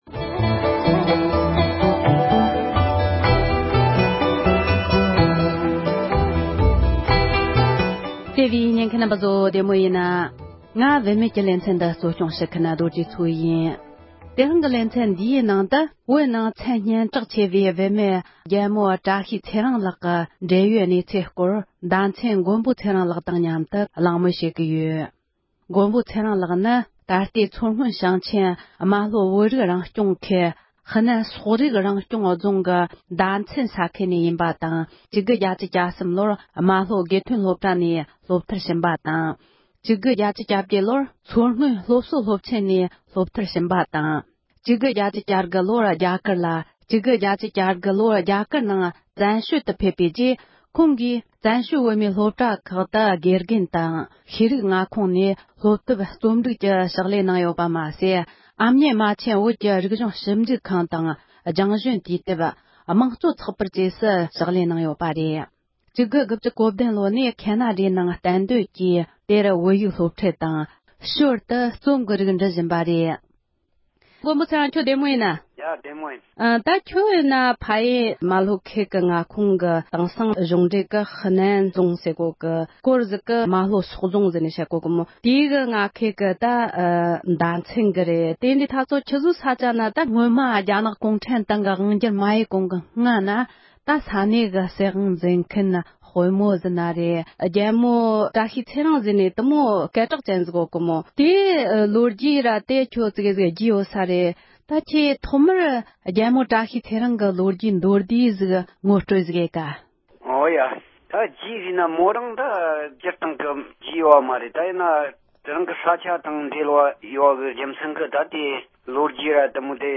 མདོ་སྨད་ཡུལ་གྱི་མཚན་གྲགས་ཆེ་བའི་རྨ་ལྷོ་སོག་རྫོང་གི་རྒྱལ་མོ་བཀྲ་ཤིས་ཚེ་རིང་གི་ལོ་རྒྱུས་སྐོར་གླེང་མོལ།